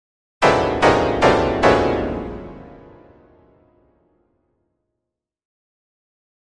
Piano Harsh Jarring Descending Chords